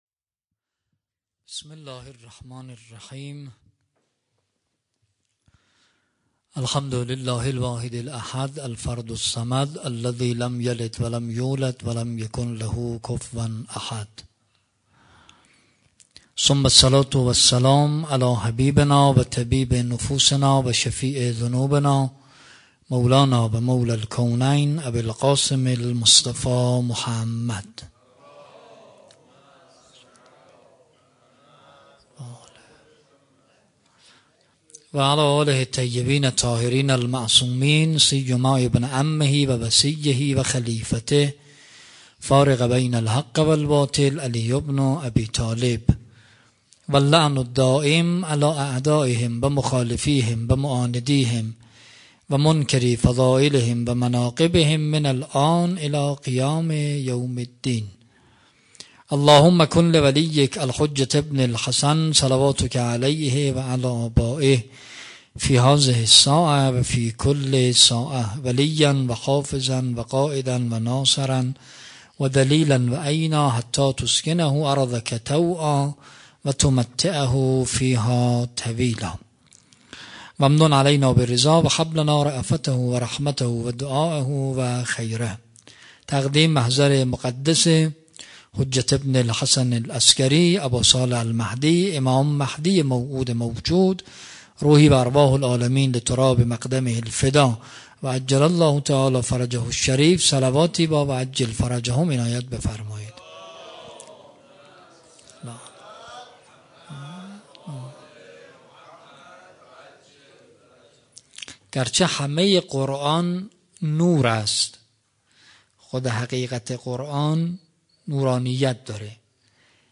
شب هفدهم رمضان 96 - ریحانة النبی - سخنرانی